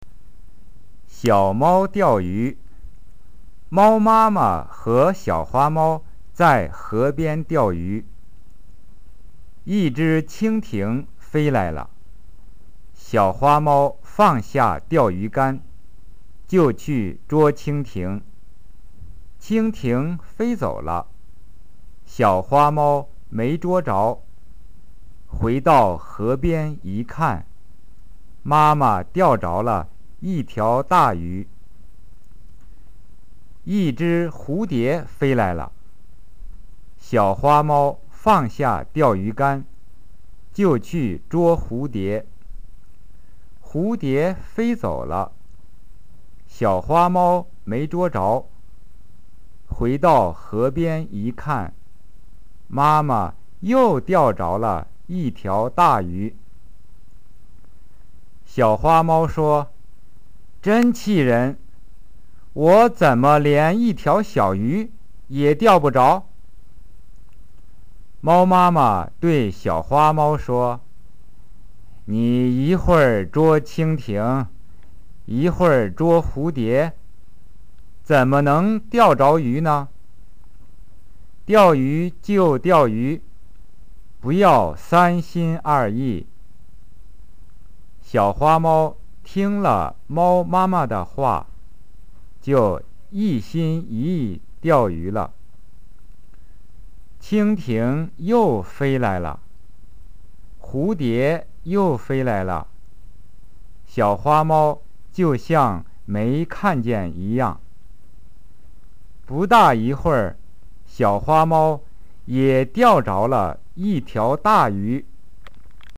リスニング練習問題（２）
発音を聞く　（２分：ゆっくり）
xiaomao-slow.mp3